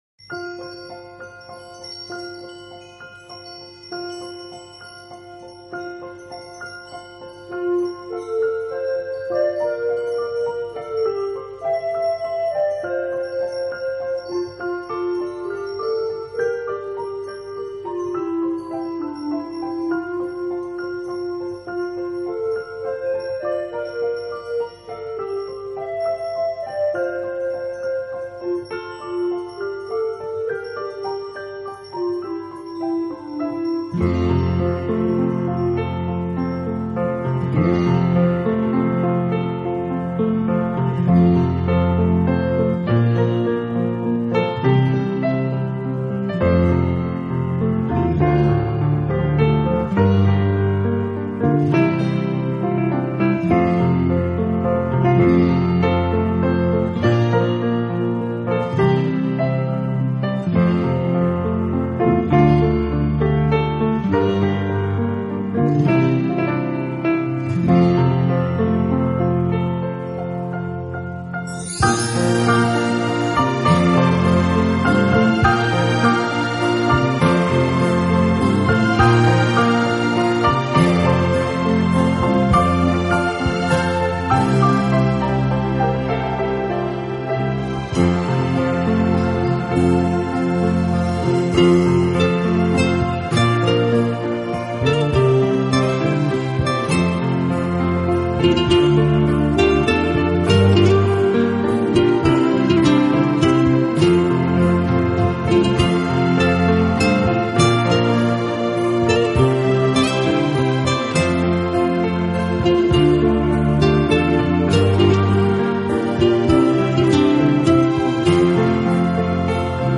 器配置，使每首曲子都呈现出清新的自然气息。